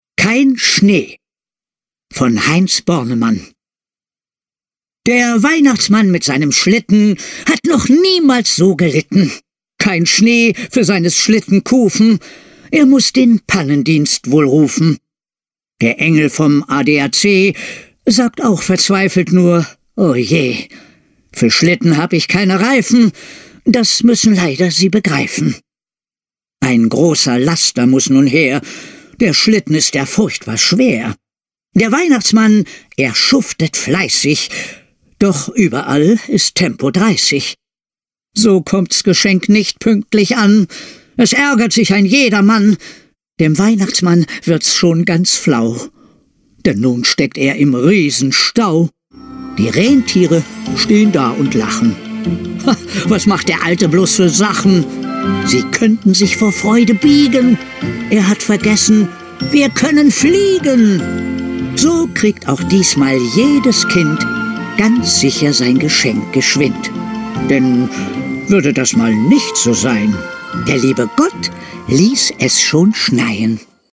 Santiago Ziesmer liest das Gedicht